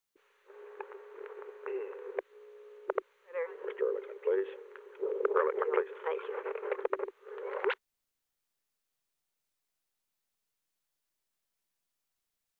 • White House operator
Location: White House Telephone
The President talked with the White House operator.